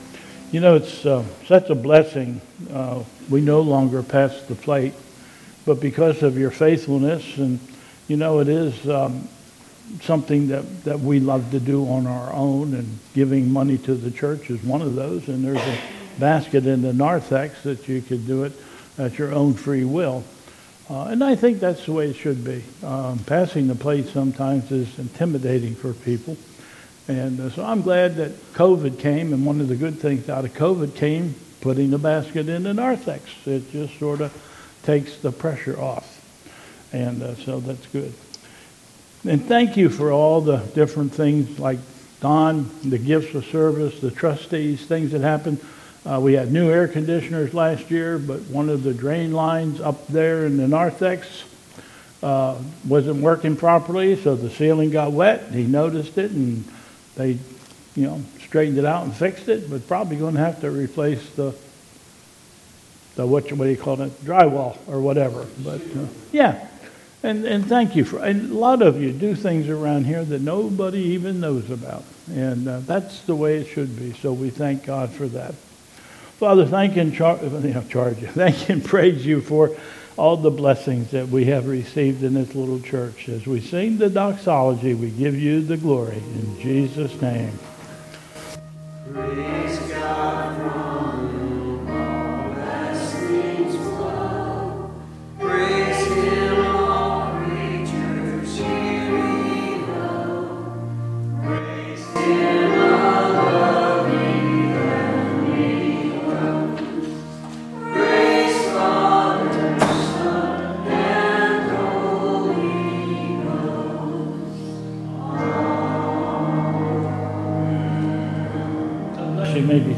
...Prayer of Thanksgiving...and singing of the Doxology